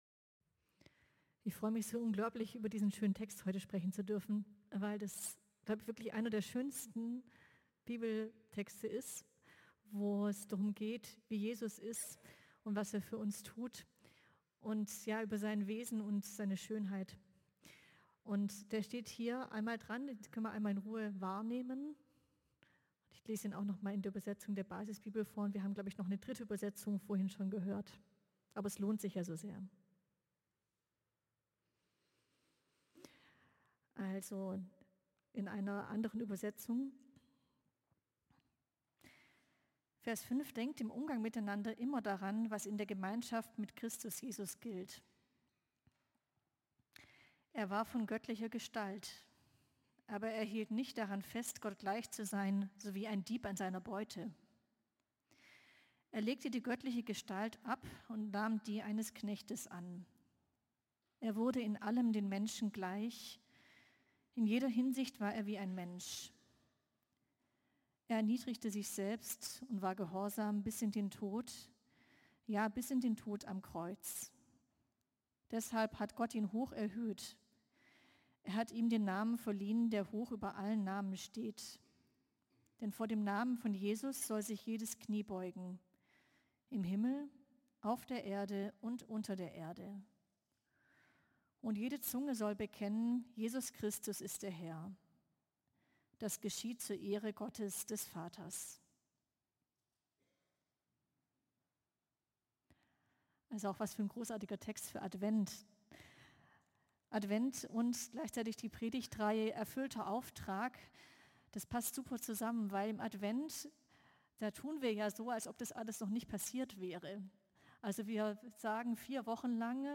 Predigten aus einANDERERGottesdienst